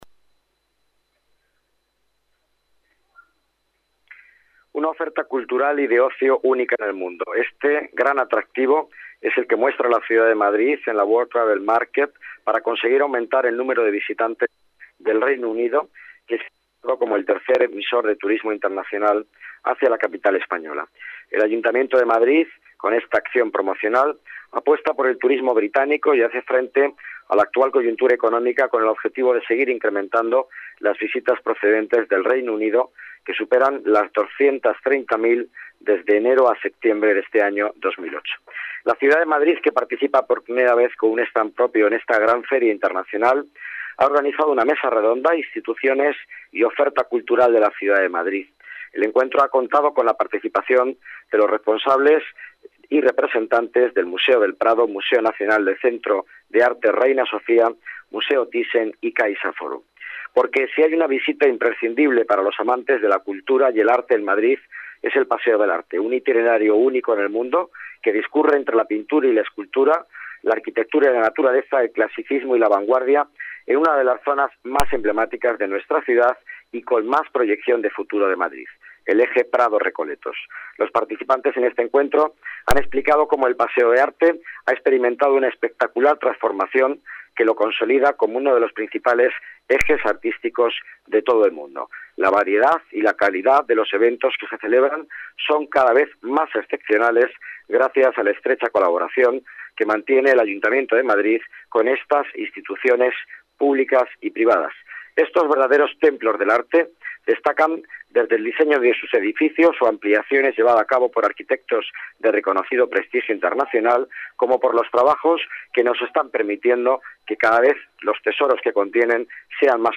Nueva ventana:Declaraciones de Miguel Ángel Villanueva en Londres